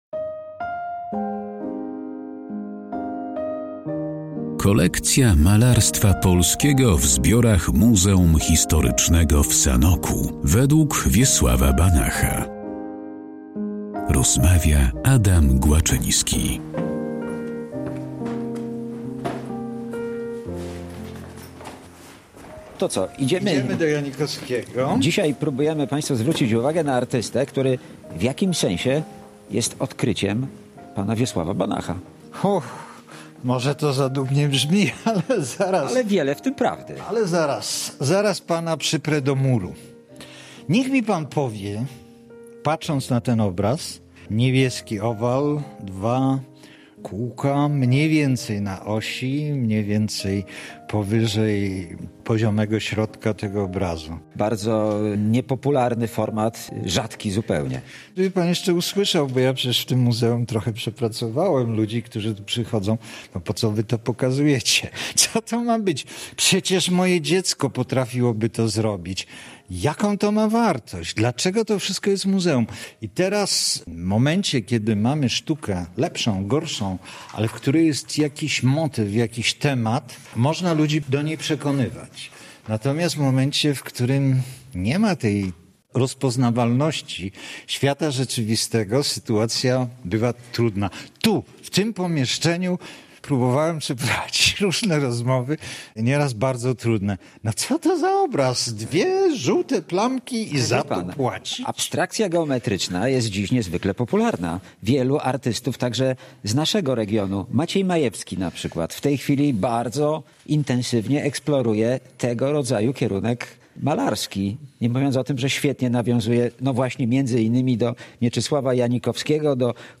O twórczości Mieczysława Janikowskiego i jego pracach znajdujących się w Muzeum Historycznym w Sanoku rozmawiają